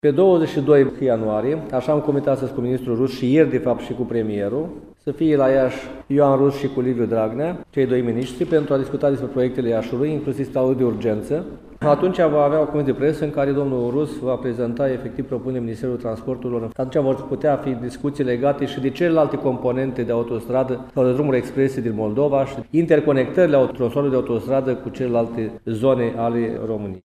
La rândul său, primarul Gheorghe Nichita, a declarat, astăzi, într-o conferință de presă, că ministrul Transportului, Ioan Rus, va fi prezent la Iași, săptămâna viitoare, pentru a oferi mai multe detalii legate de autostrada care va lega Moldova de Transilvania.